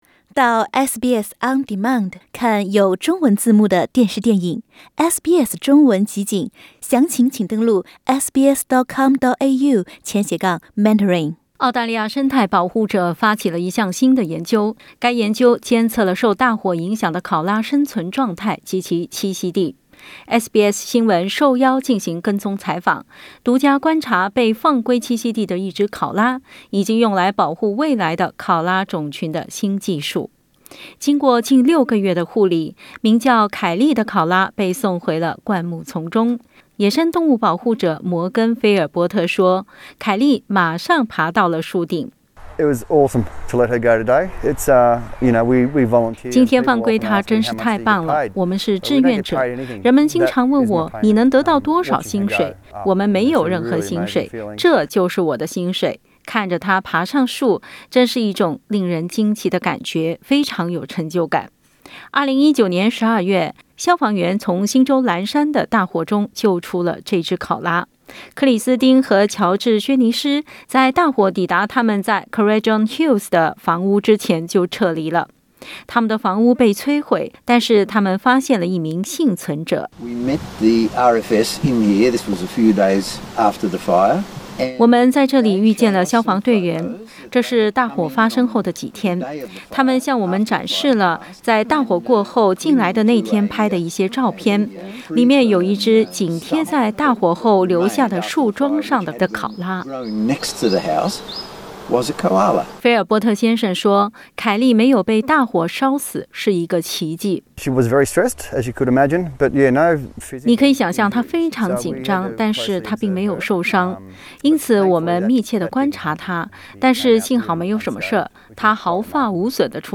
SBS新闻受邀进行跟踪采访，独家观察被放归栖息地的一只考拉，以及用来保护未来的考拉种群的新技术。 点击图片收听详细报道。